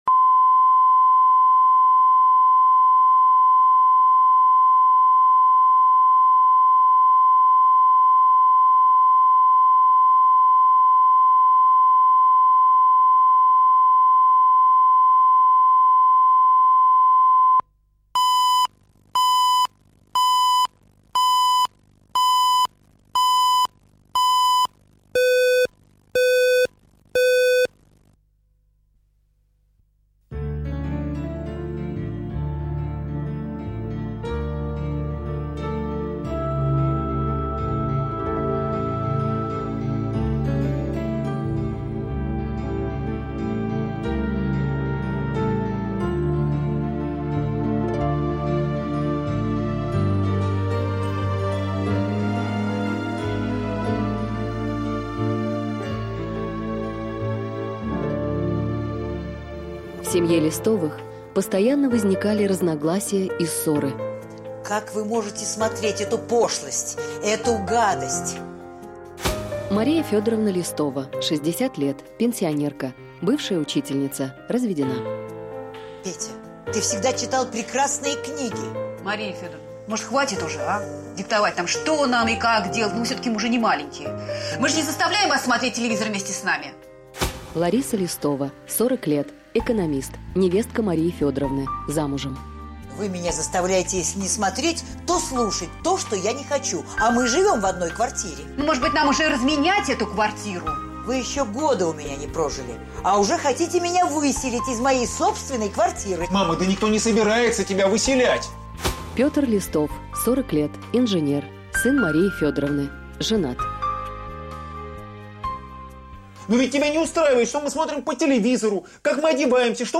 Аудиокнига Не в ногу со временем | Библиотека аудиокниг